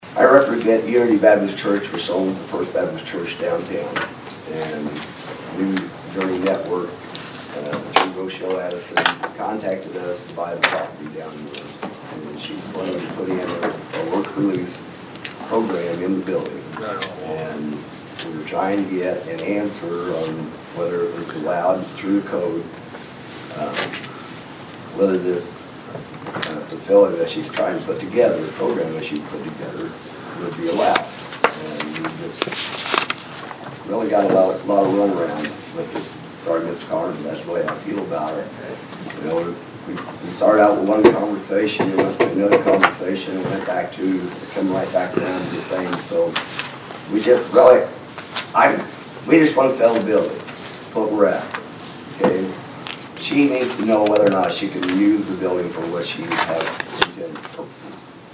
There was a long discussion at Monday’s Vandalia City Council meeting on the possible sale of the former First Baptist Church building in downtown Vandalia.